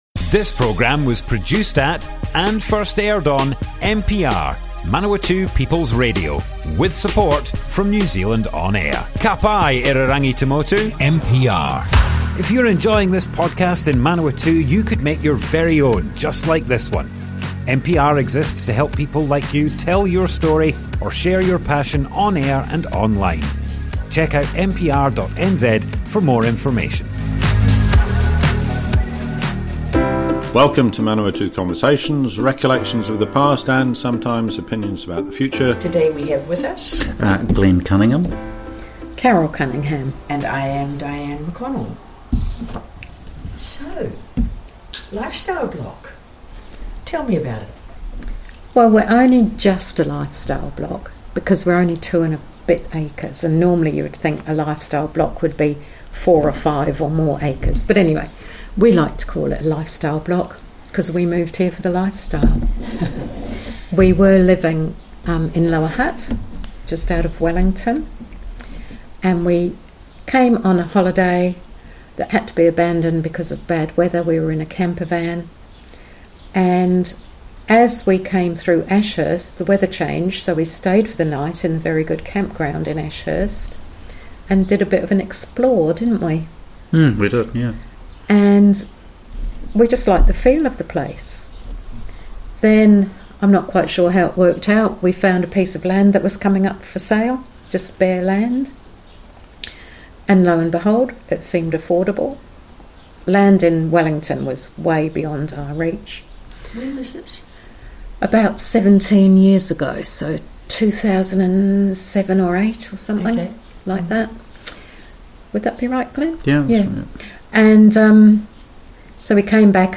Manawatu Conversations Object type Audio More Info → Description Broadcast on Manawatu People's Radio, 20th January 2026.
oral history